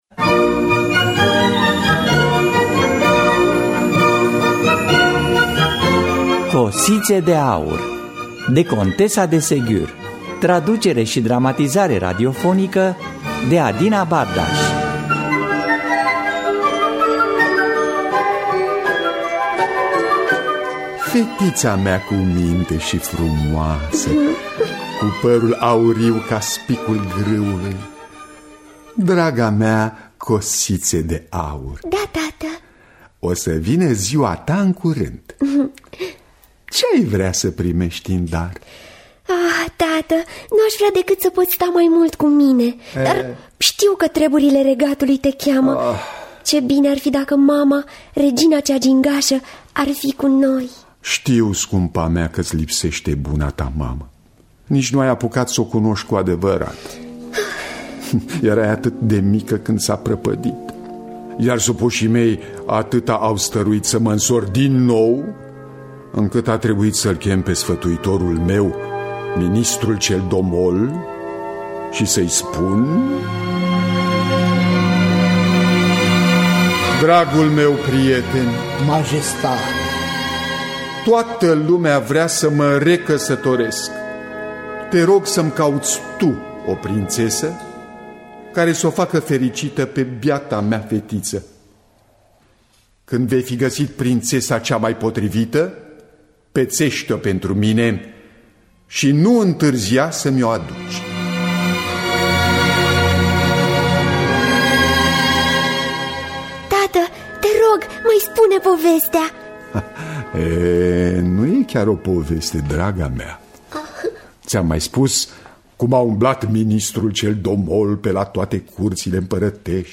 Traducerea, dramatizarea şi adaptarea radiofonică